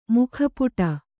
♪ mukha puṭa